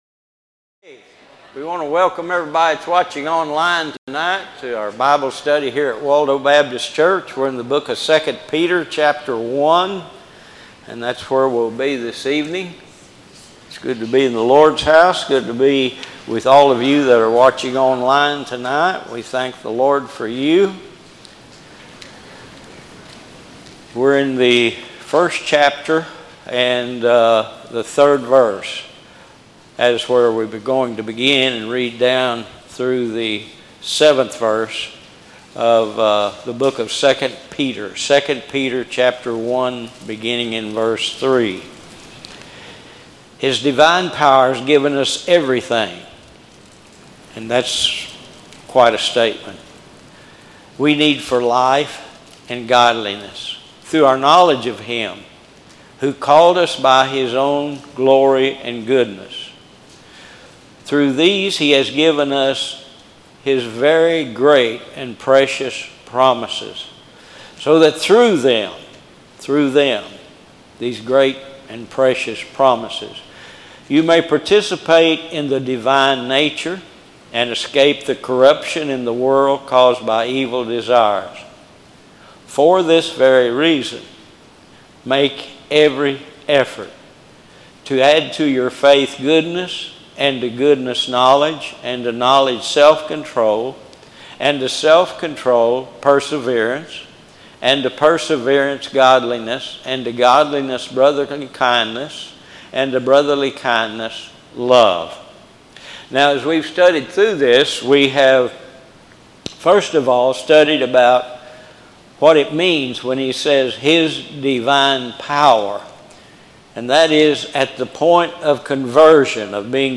Sermons | Waldo Baptist Church